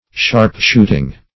Search Result for " sharpshooting" : The Collaborative International Dictionary of English v.0.48: Sharpshooting \Sharp"shoot`ing\, n. A shooting with great precision and effect; hence, a keen contest of wit or argument.